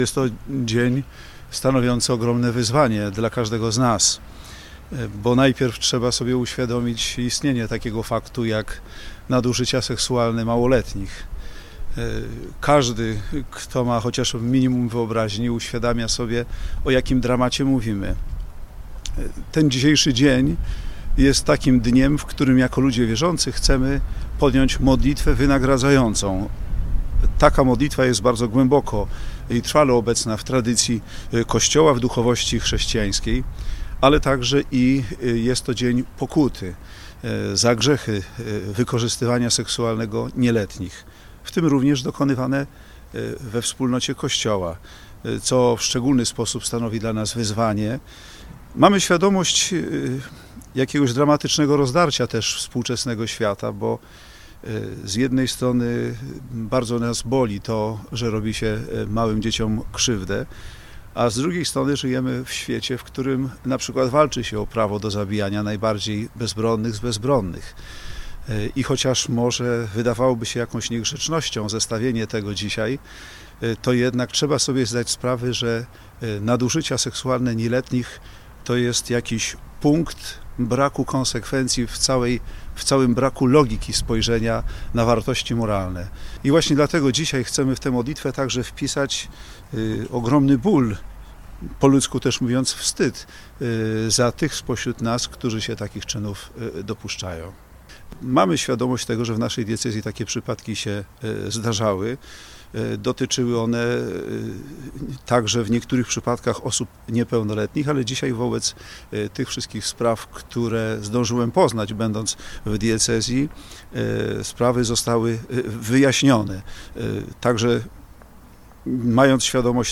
Przed Mszą św. i drogą krzyżową biskup koszalińsko-kołobrzeski spotkał się na briefingu z mediami.
bp Zbigniew Zieliński_wypowiedź dla mediów.mp3